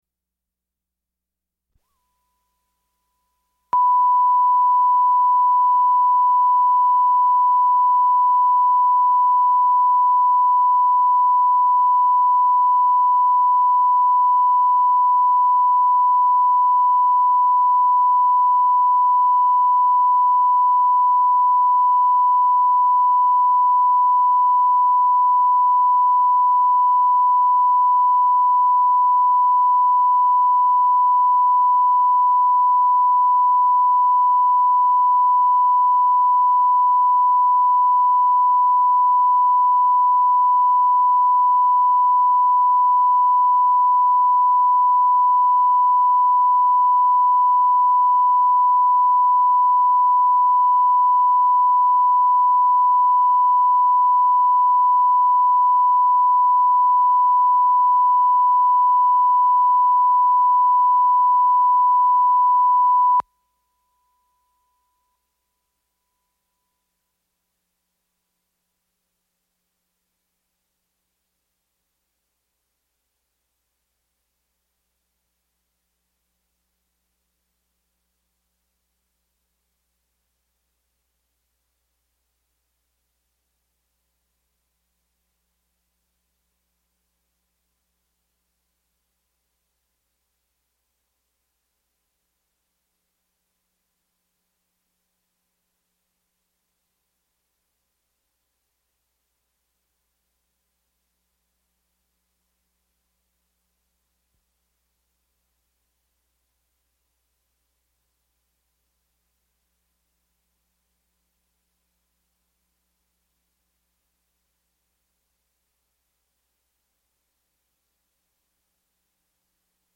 Cartoonist Gary Larson on What's Beautiful and What's Ugly in Nature | Fresh Air Archive: Interviews with Terry Gross